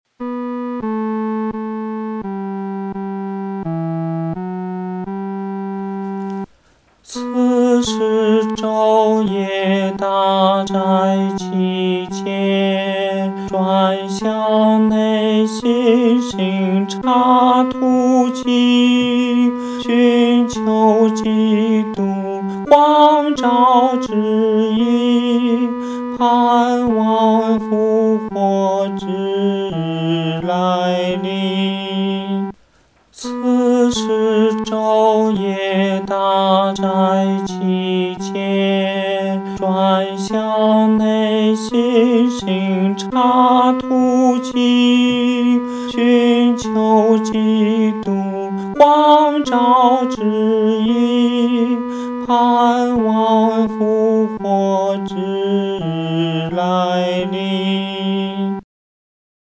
男高
这首诗歌充满着虔敬和恳切，我们在弹唱时的速度不宜太快。